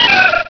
-Replaced the Gen. 1 to 3 cries with BW2 rips.